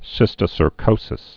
(sĭstĭ-sər-kōsĭs)